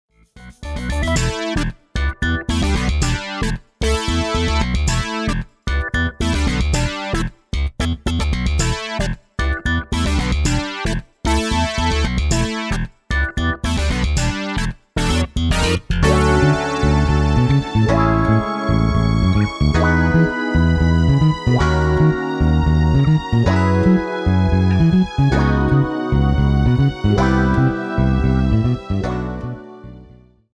A play-along track in the style of funk.